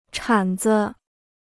铲子 (chǎn zi): shovel; spade.